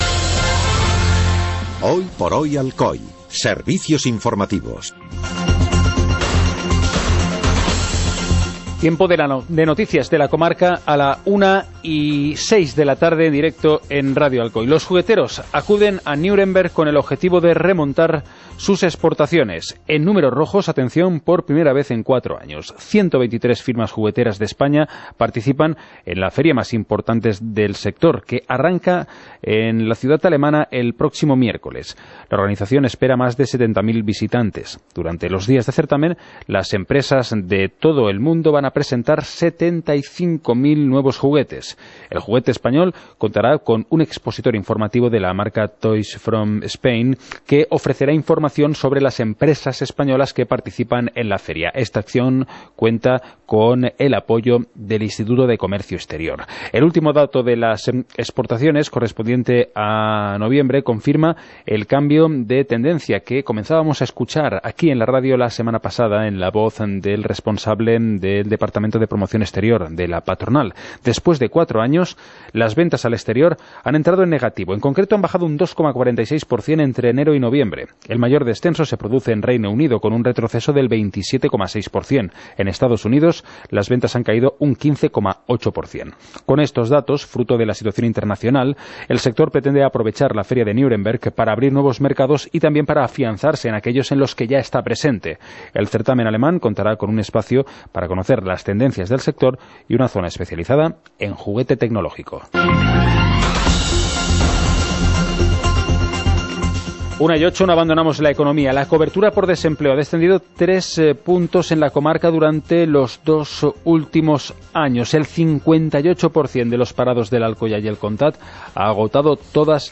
Informativo comarcal - lunes, 30 de enero de 2017